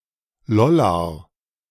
Lollar (German pronunciation: [ˈlɔlaʁ]